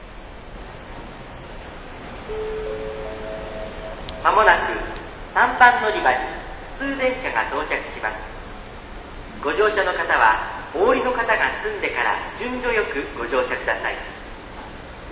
●スピーカー：ソノコラム・小
●音質：D
旧 ３番のりば 普通・接近放送　(75KB/15秒）